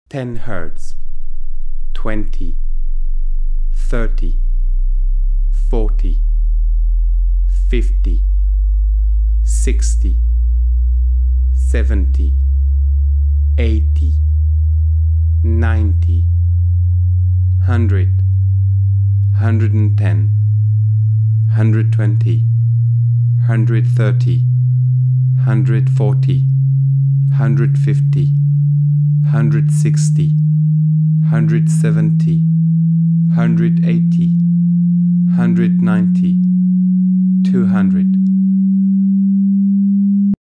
A -6 dbFS sweeping sine tone, from 10 Hz (supposedly inaudible) to 200 Hz (supposedly played back by all sound systems, including those smallish laptop speakers). On the top of the test tone, a voiceover tells you which frequency is currently playing.
low-frequency-response-and-subwoofer-test.mp3